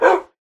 bark3.ogg